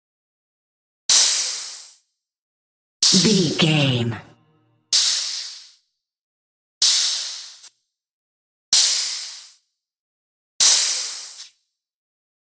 Compressed air
Sound Effects
urban